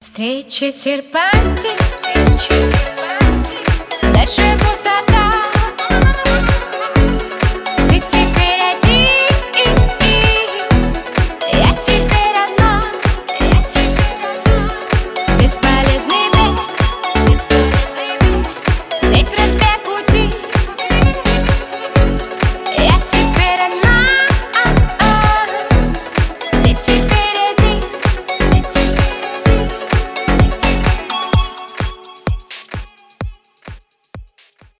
2017 » На Девушку » Русские » Поп Скачать припев